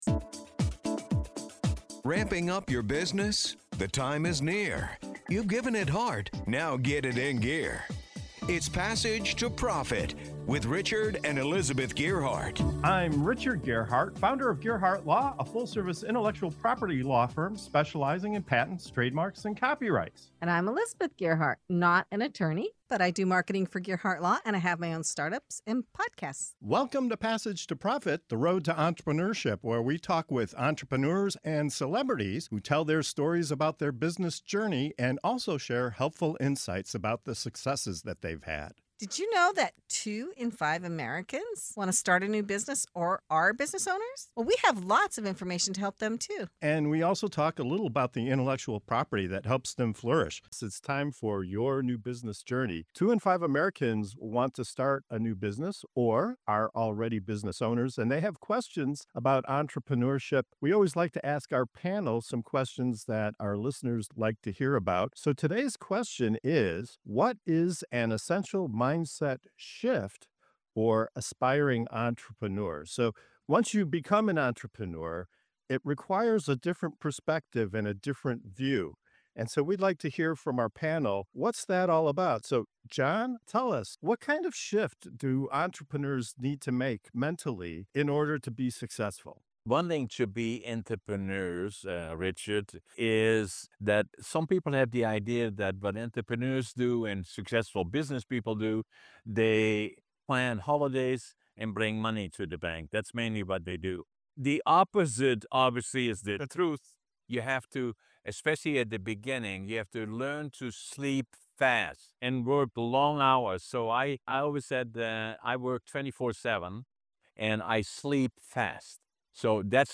In this segment of “Your New Business Journey” on Passage to Profit Show, our panel tackles the essential changes aspiring entrepreneurs must make to succeed, from embracing failure as a stepping stone to understanding that entrepreneurship is less about being your own boss and more about serving others. Tune in to hear insights on resilience, creativity, and the real grit it takes to navigate the highs and lows of the entrepreneurial journey.